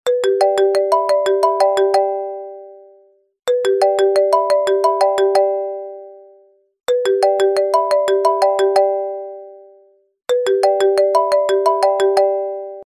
Отличного качества, без посторонних шумов.